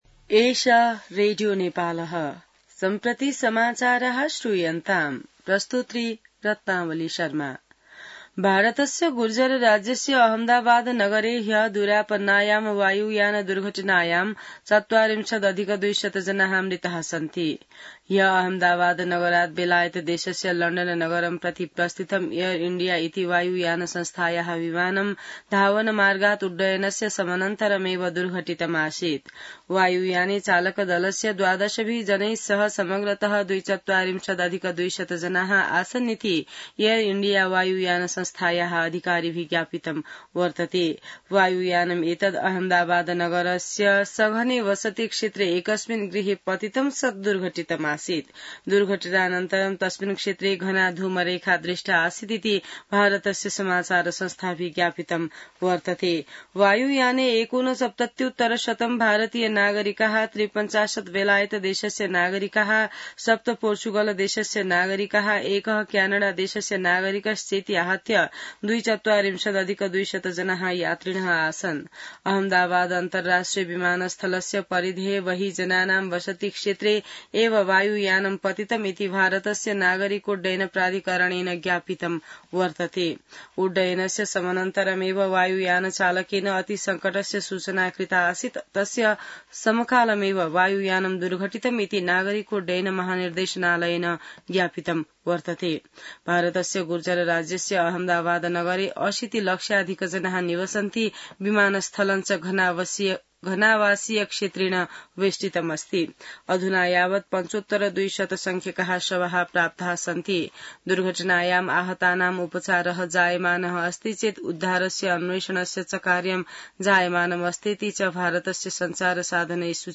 An online outlet of Nepal's national radio broadcaster
संस्कृत समाचार : ३० जेठ , २०८२